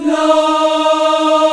AFROLA D#4-R.wav